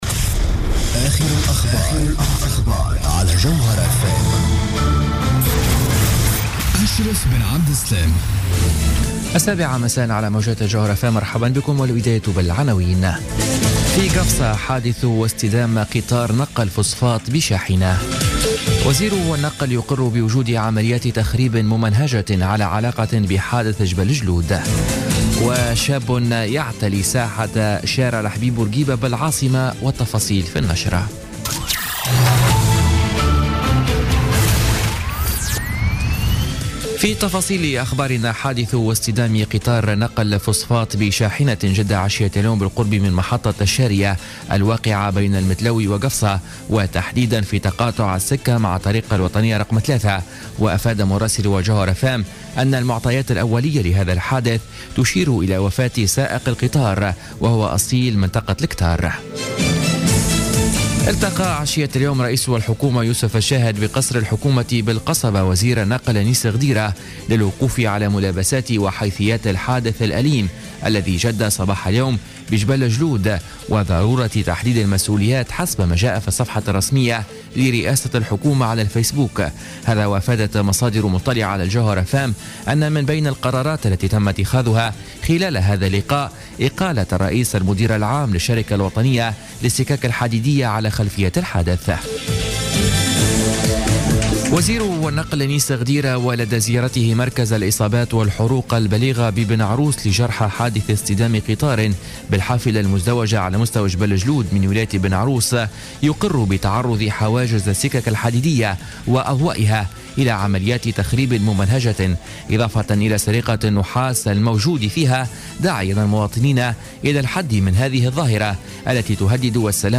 نشرة أخبار السابعة مساء ليوم الاربعاء 28 ديسمبر 2016